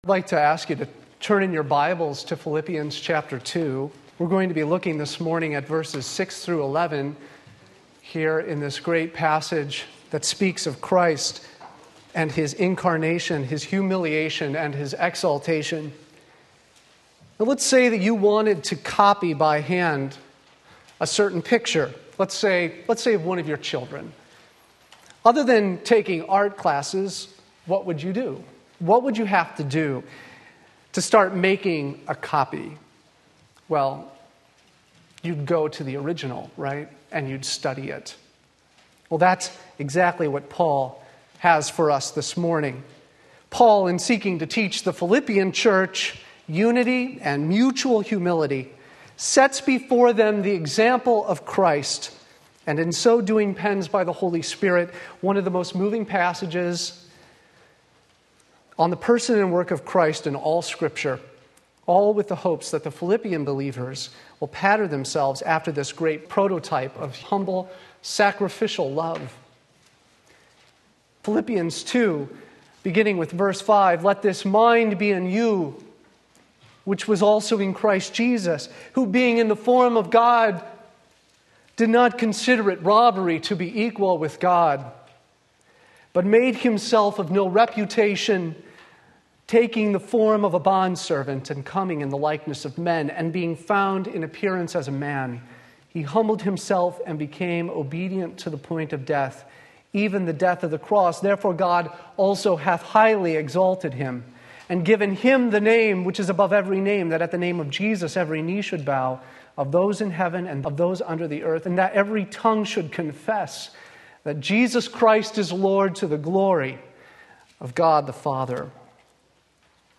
Sermon Link
Christ's incarnation is a great example of Christian humility in three ways. We look at this glorious text this morning as we gather around the table.